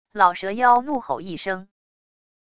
老蛇妖怒吼一声wav音频